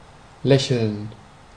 Ääntäminen
IPA: [ˈsmiːˀlə]